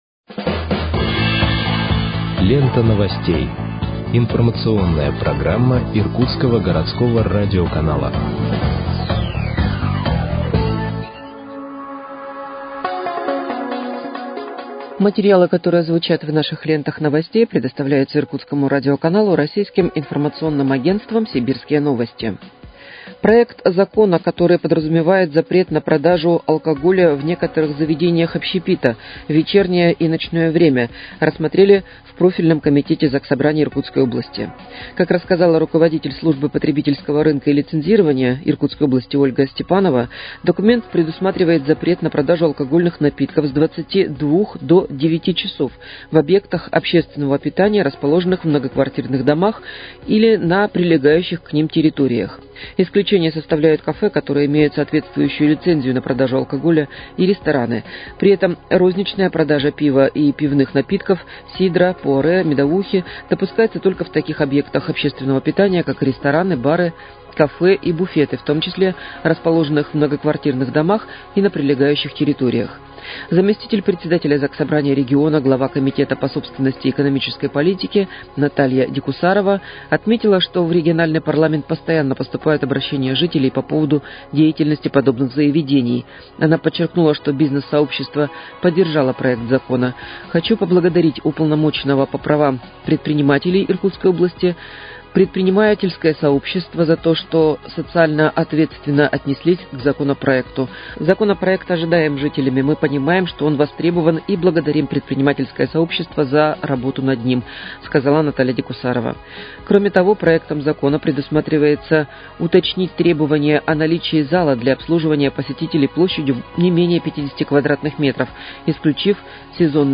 Выпуск новостей в подкастах газеты «Иркутск» от 16.10.2024 № 1